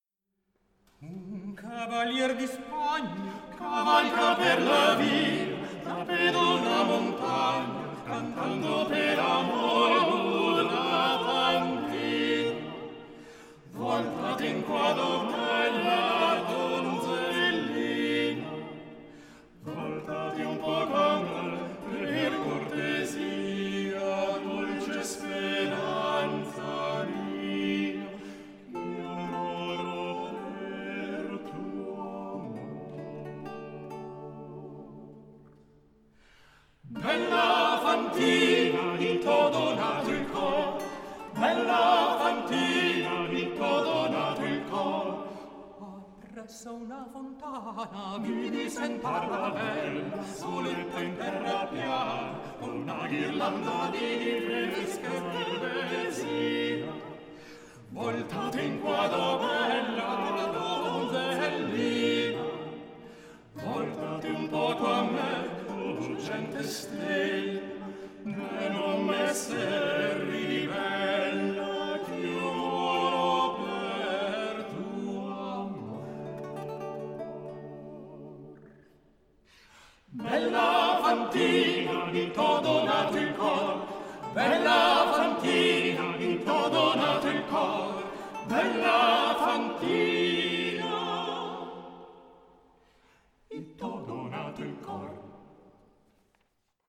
Un quartetto di musicisti cantanti, radunati insieme per l’occasione del Festival Cantar di Pietre dedicato a Venezia, ha dato vita a un progetto che si muove sulla messa in musica della storia di Orlando attingendo a fonti venete e a una traduzione in veneziano del poema dell’Ariosto.
voce, traversa
voce, claviciterio, organetto
voce, liuto
voce, arpa
Alcuni ascolti dal concerto di Biasca: